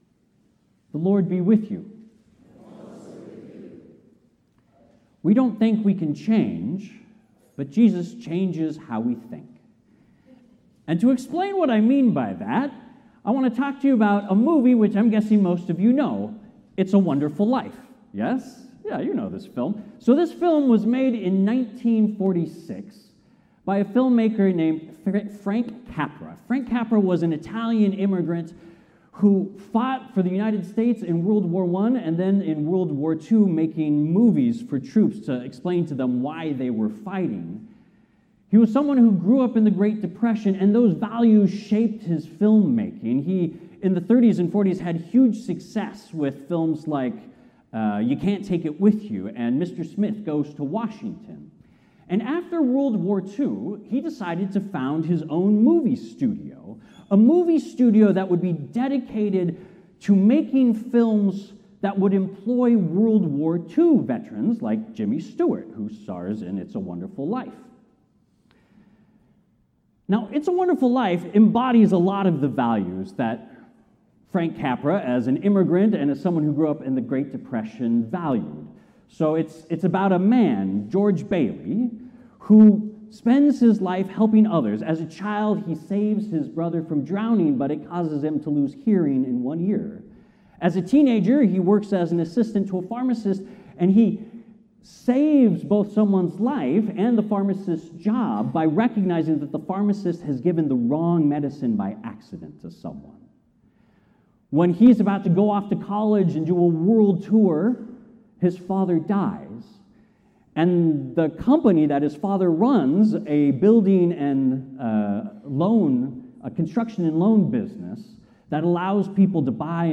As you listen to the sermon, here are some questions you can reflect on to deepen your spiritual connection with God: